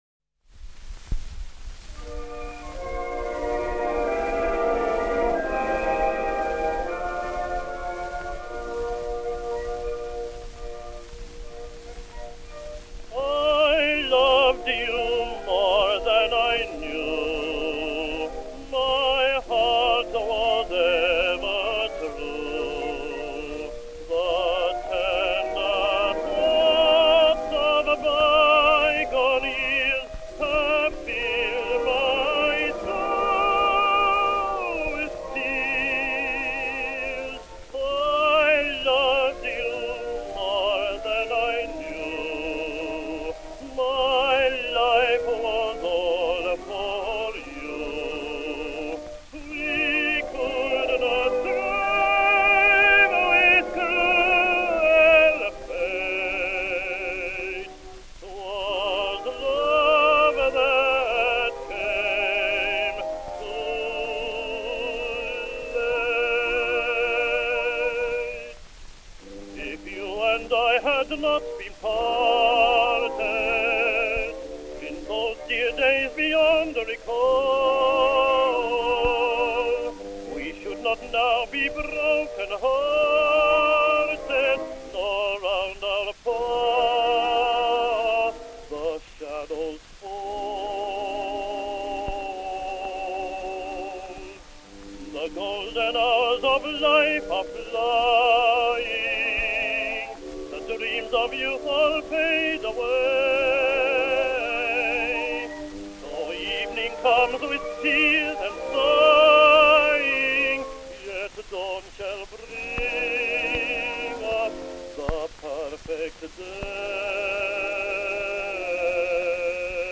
The Phantom Melody - song with orchestra (= I loved you more than I knew)
orchestra